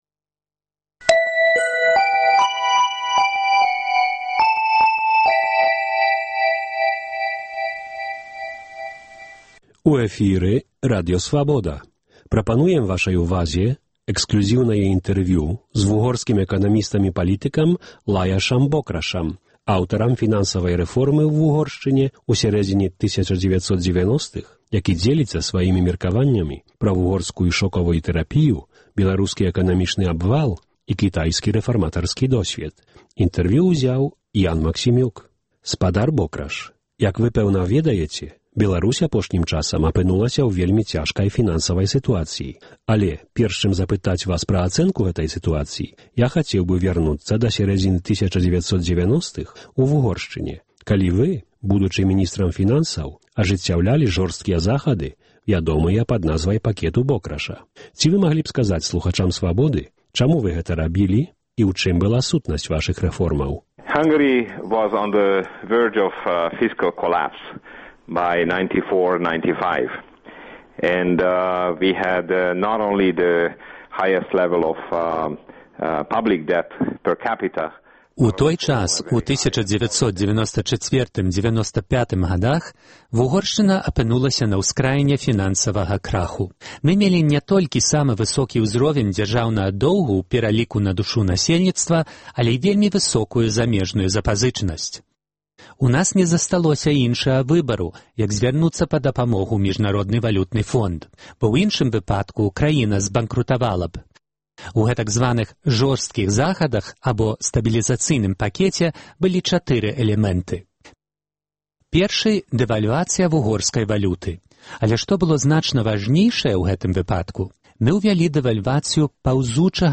Вугорскі эканаміст-рэфарматар Лаяш Бокраш у эксклюзіўным інтэрвію гаворыць пра вугорскую «шокавую тэрапію», беларускі абвал і кітайскі досьвед.